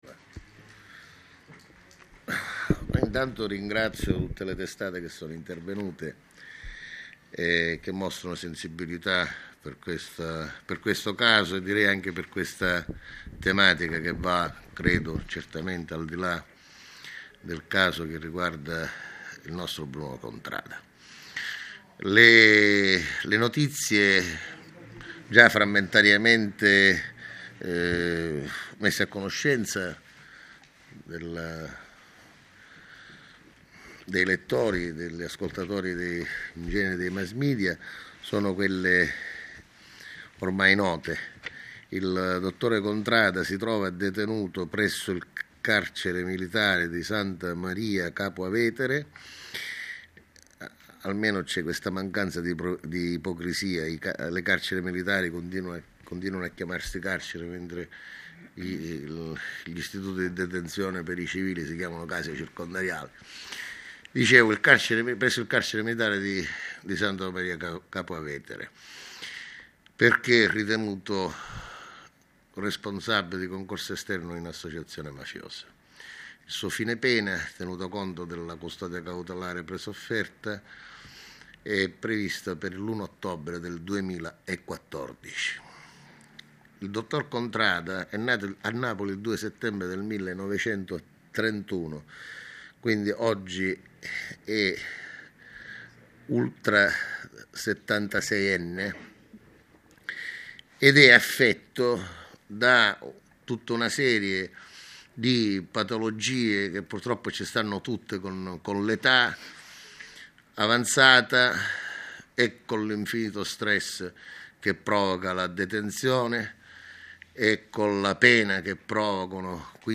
(file-audio conferenza stampa caso Contrada